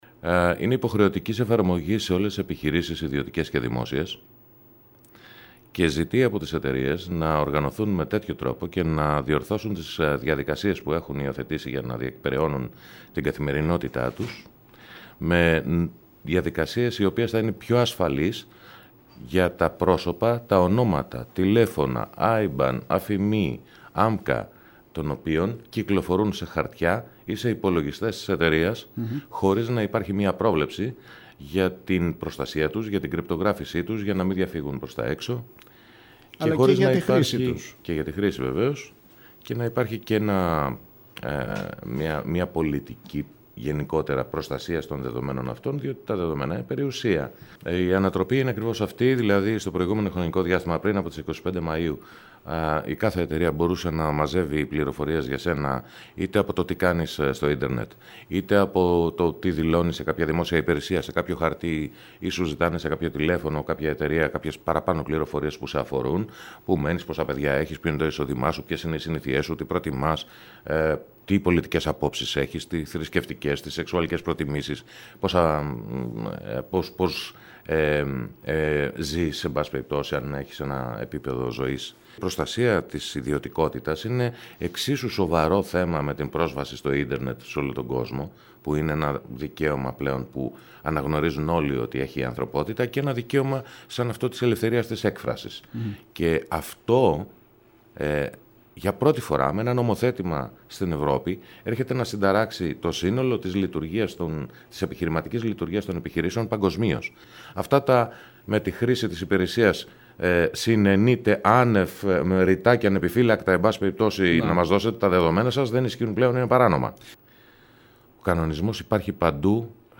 ακούμε απόσπασμα της συνέντευξης